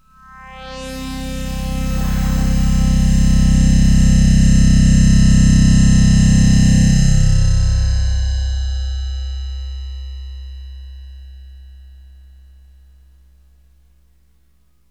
AMBIENT ATMOSPHERES-2 0001.wav